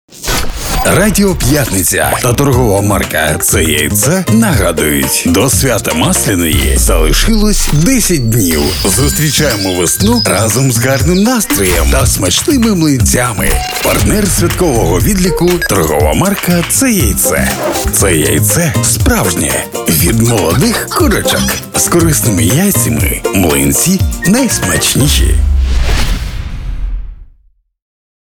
Также для запуска рекламной кампании на радио было записано 5 разных радио роликов для 5-ти радиостанций с уникальной и эмоциональной подачей, задорным и привлекательным текстом (слушайте ниже!).